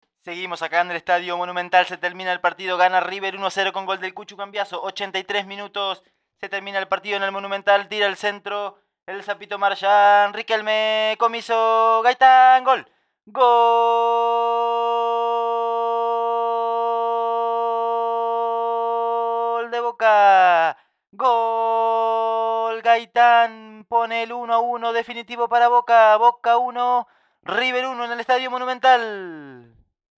Relato 004